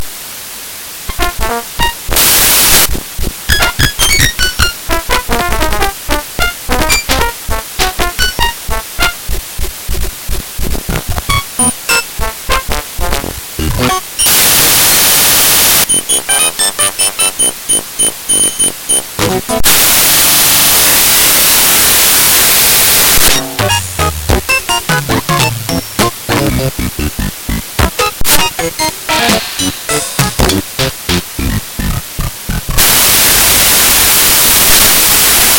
Kindlasti klikkike "Muusika" peal ja siis kuulete meie fotot polüfooniliselt.